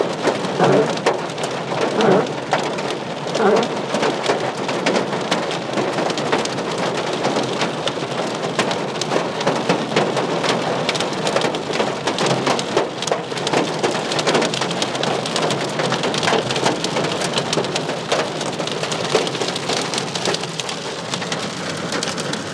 Driving in hailstorm in Islamabad 9th March
Huge hailstones hammering the car in Isloo